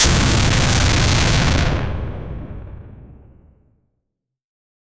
Royalty-free fire sound effects
Loopable. 0:10 Burning fire torch 0:10 Fire arrow hit, small explosion, embers crackling 0:03 The sound of a fire burning in old tent cloth 0:10 Epic fire charge up on weapon 0:05
epic-fire-charge-up-on-p2ijicgx.wav